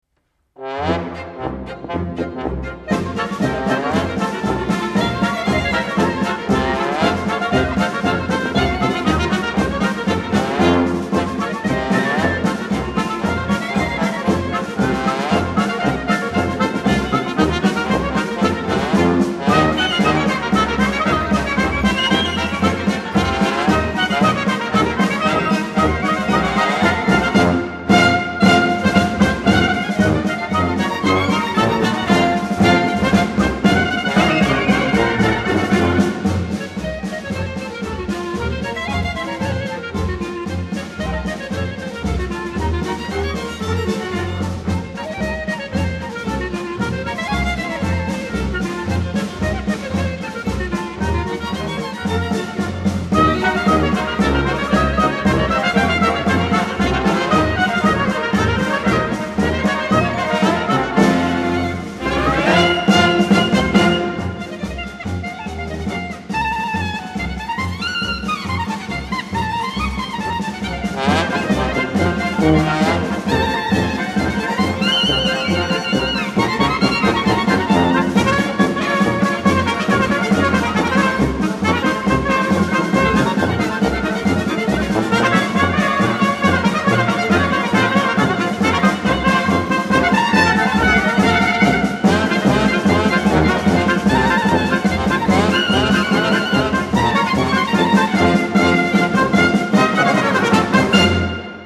Simcha  folk ebraico